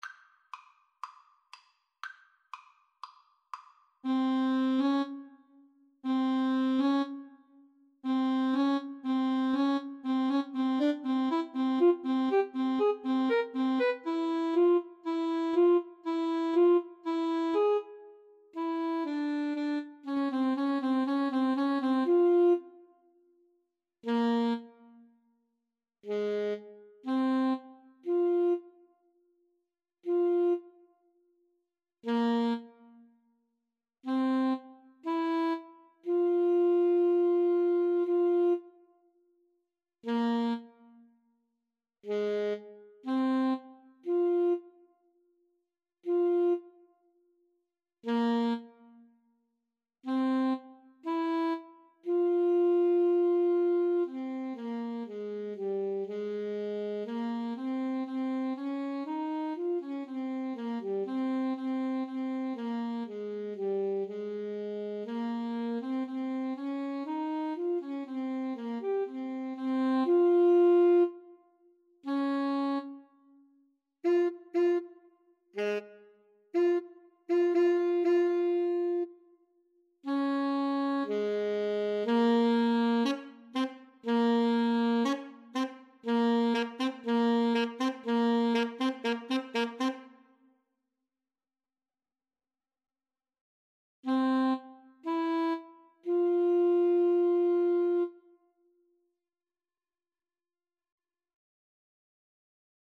F minor (Sounding Pitch) C minor (French Horn in F) (View more F minor Music for Alto Saxophone Duet )
Allegro con fuoco (View more music marked Allegro)
Alto Saxophone Duet  (View more Easy Alto Saxophone Duet Music)
Classical (View more Classical Alto Saxophone Duet Music)